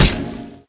poly_explosion_holy02.wav